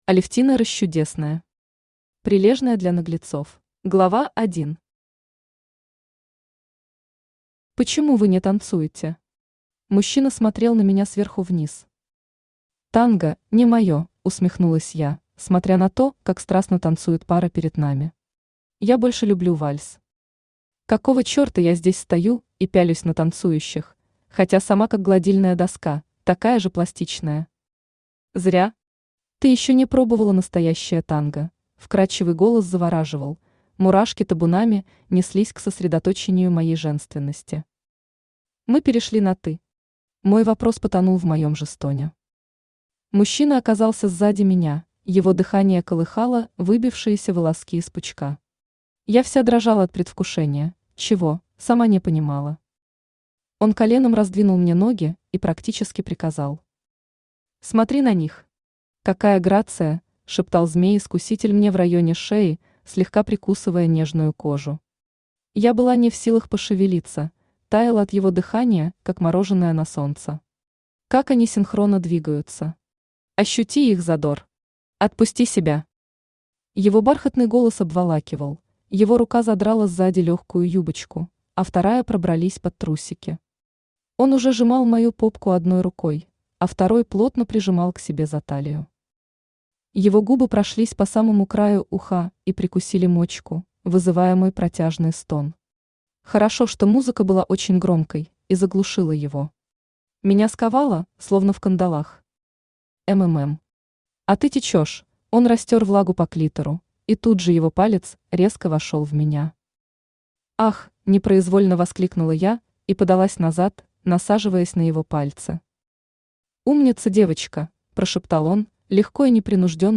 Аудиокнига Прилежная для наглецов | Библиотека аудиокниг
Aудиокнига Прилежная для наглецов Автор Алевтина Расчудесная Читает аудиокнигу Авточтец ЛитРес.